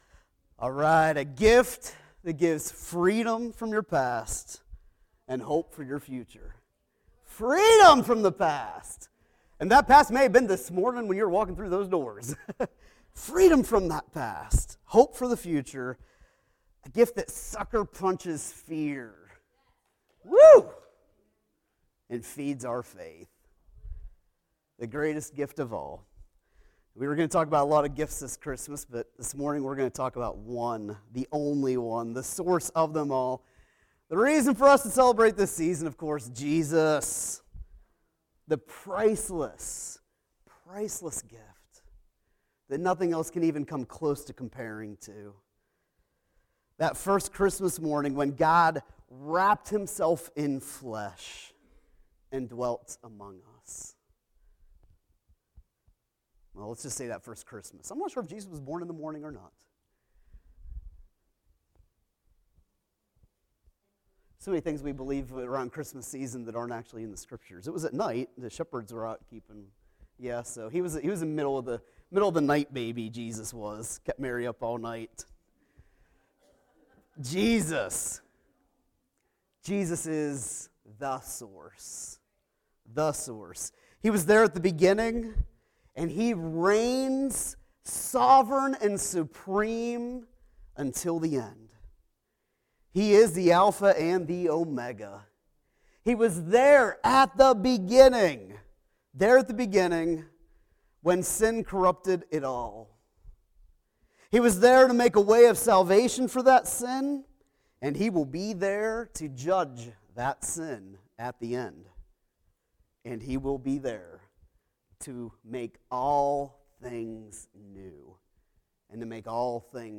This morning, we unwrap the first gift of Christmas, which is the entire reason for us to celebrate this season; Jesus!